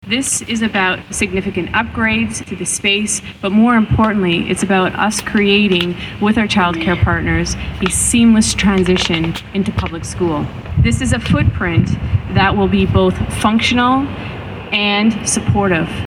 Groundbreaking ceremony held at West Elgin Public School